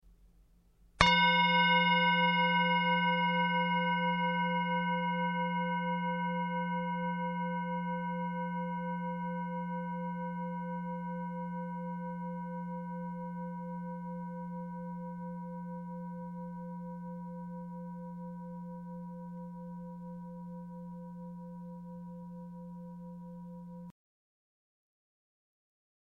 Tibetische Klangschale - UNIVERSALSCHALE
Durchmesser: 18,8 cm
Grundton: 177,74 Hz
1. Oberton: 511,49 Hz